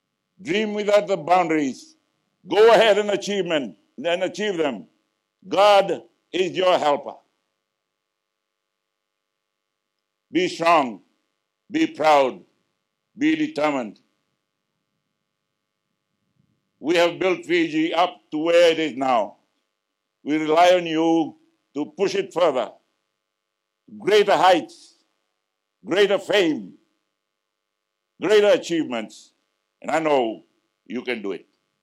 While reflecting on his journey at the QVS Pass-Out Parade in Tailevu North yesterday, he says a lot has been invested in them as they expect a reward with great performances.